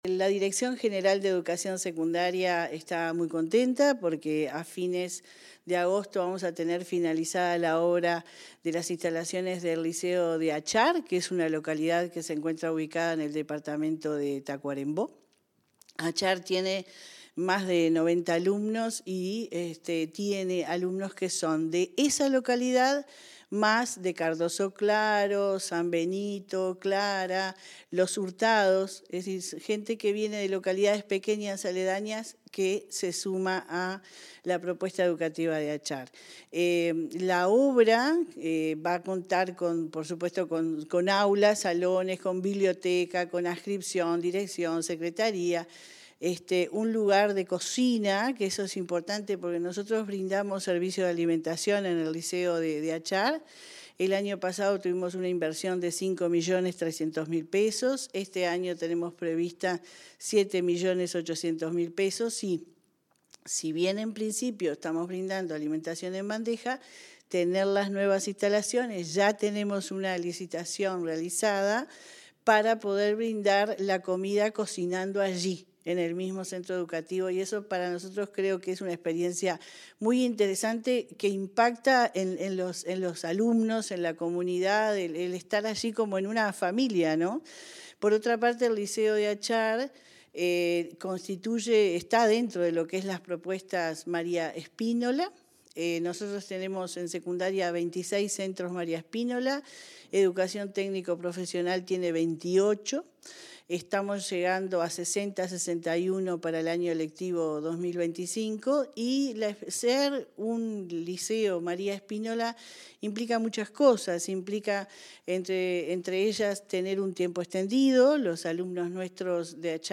Entrevista a la directora de Secundaria, Jenifer Cherro